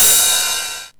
RIDE 4.wav